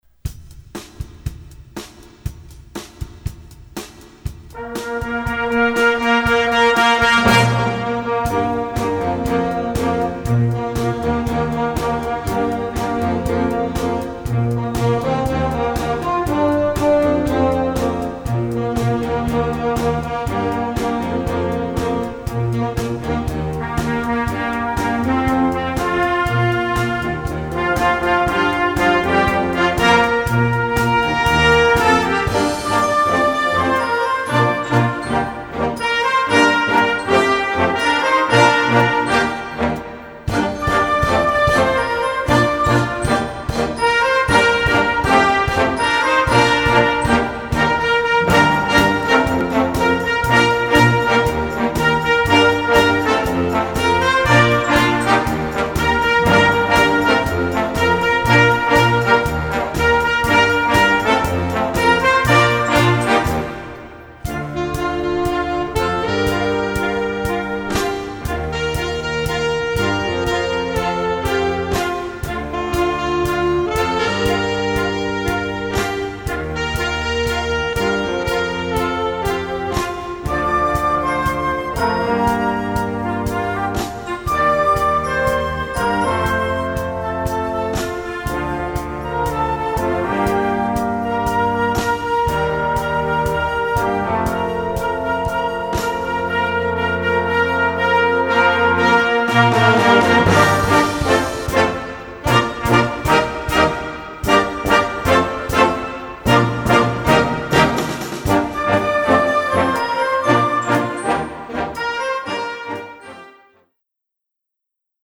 Gattung: Moderner Einzeltitel
Besetzung: Blasorchester
Dank der stürmischen Rockbeats und mitreißenden Riffs